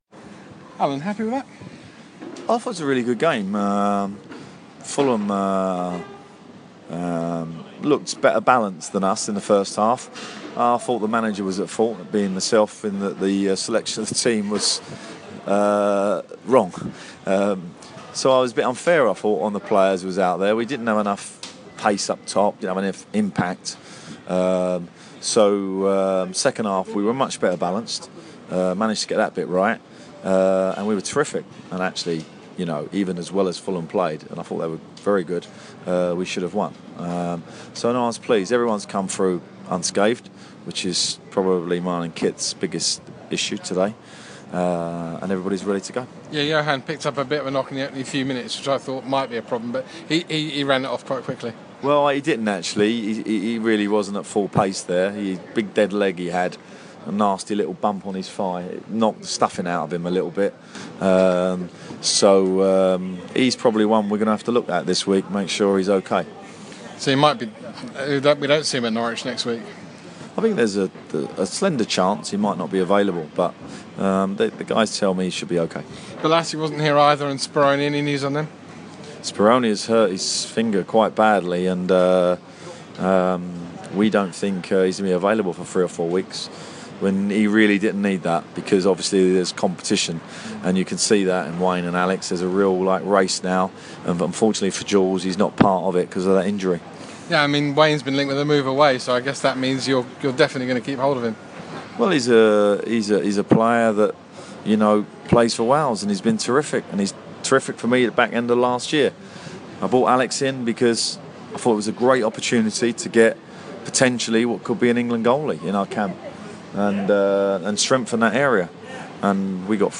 BBC London spoke to Alan Pardew after draw with Fulham